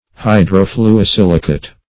Search Result for " hydrofluosilicate" : The Collaborative International Dictionary of English v.0.48: Hydrofluosilicate \Hy`dro*flu`o*sil"i*cate\, n. (Chem.) A salt of hydrofluosilic acid; a silicofluoride.